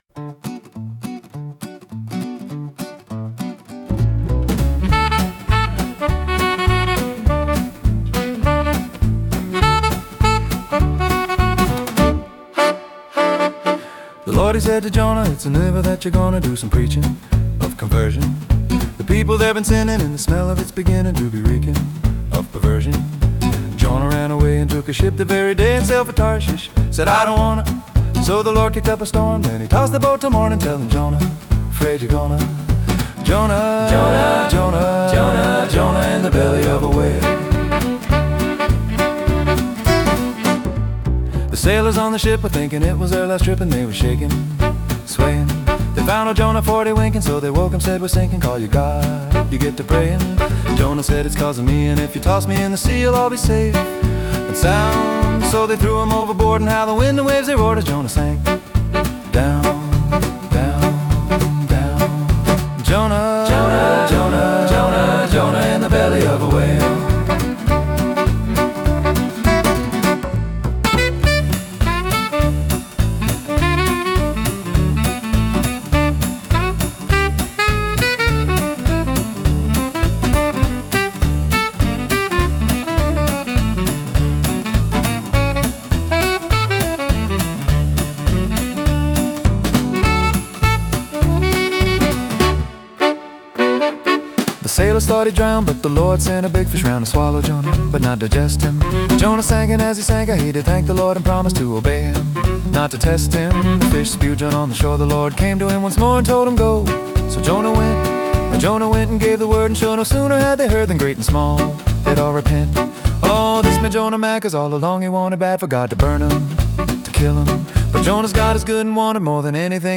Comedy Swing